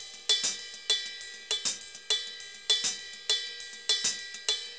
Ride_Baion 100_2.wav